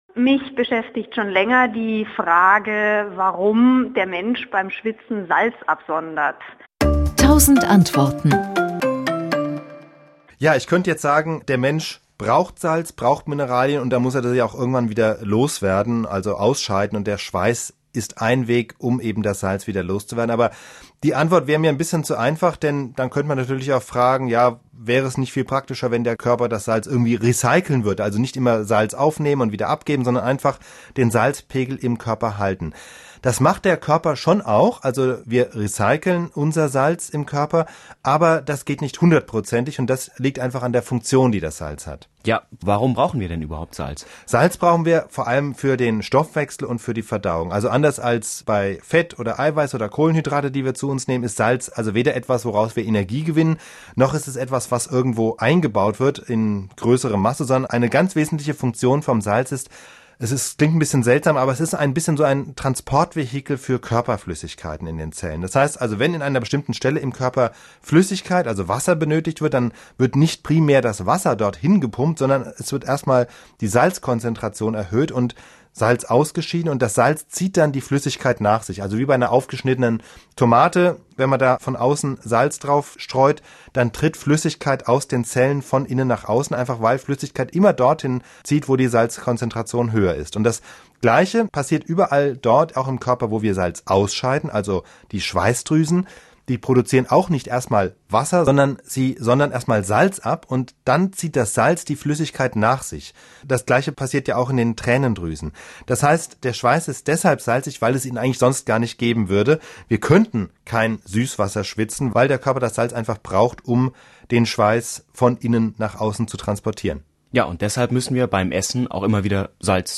Gäste aus der Wissenschaft erklären im Wechsel jeden Tag ein kleines Stückchen Welt.